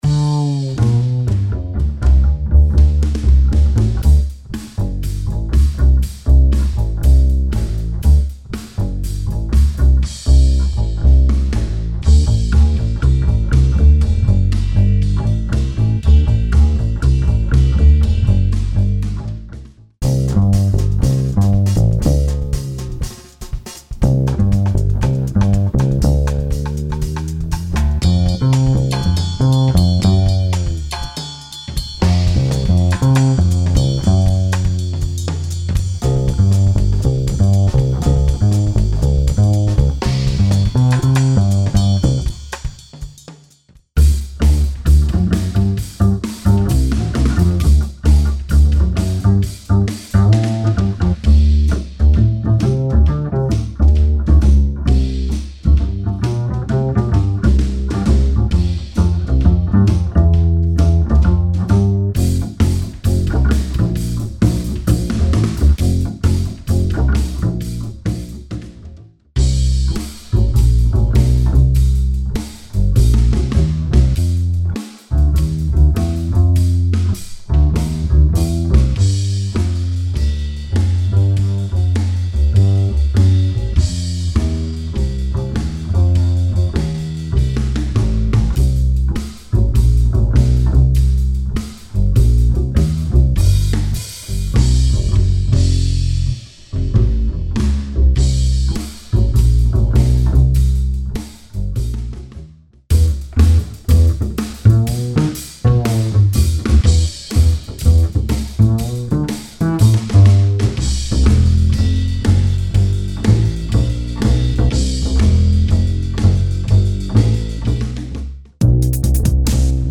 Behold! The mother of all Jazz loops is now a click away with this massive MegaPack of live Jazz loops and samples.
Download Loops and Samples 67-240 Bpm
upright_bass2_demo.mp3